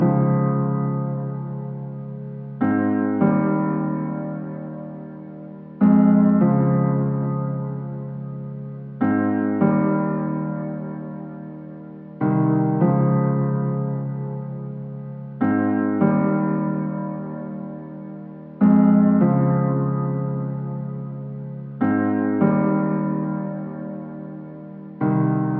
描述：寒假钢琴第一部分
标签： 150 bpm House Loops Piano Loops 4.31 MB wav Key : Unknown
声道立体声